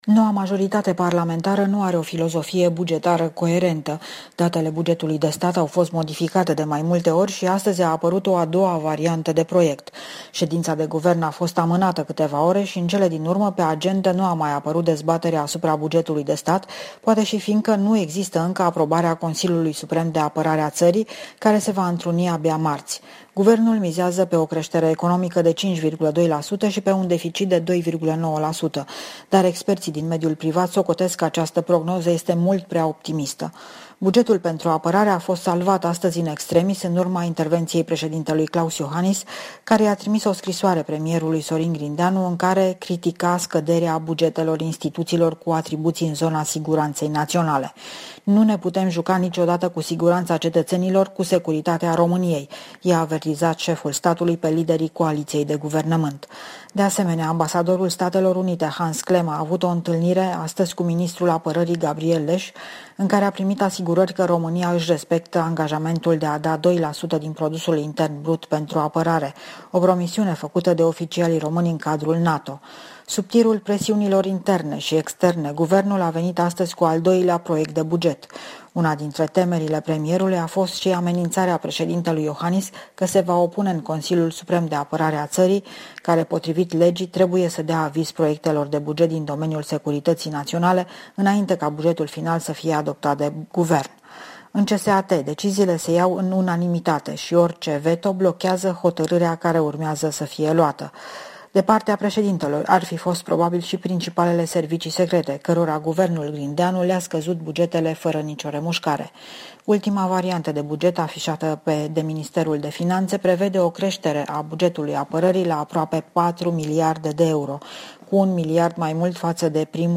Corespondența zilei de la București